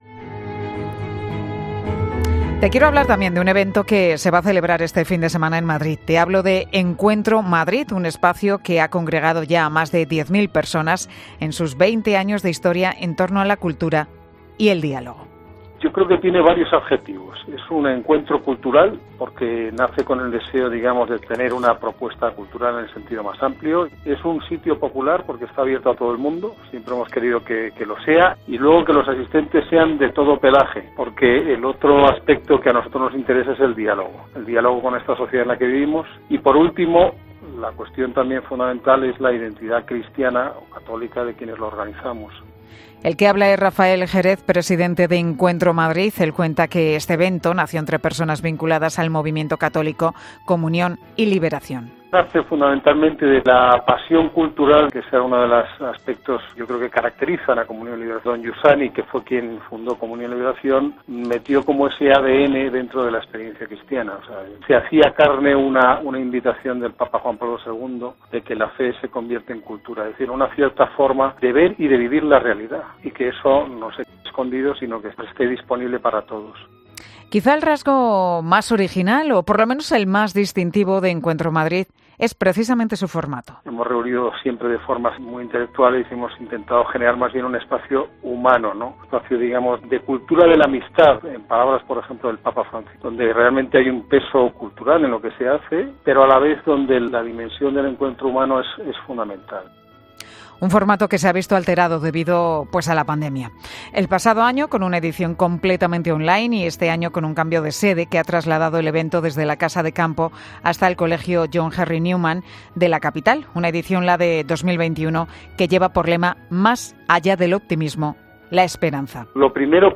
Una de las ponentes, la escritora Ana Iris Simón, ha estado en 'Mediodía COPE'